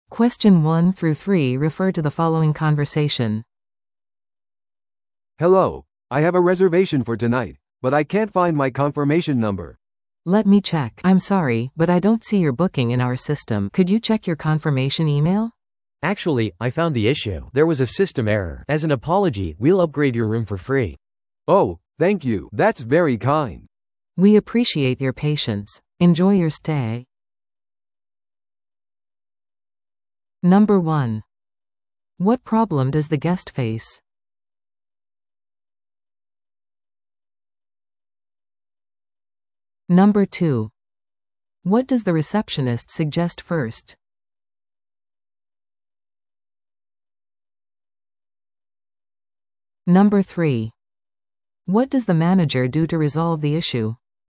TOEICⓇ対策 Part 3｜ホテル予約トラブル解決 – 音声付き No.28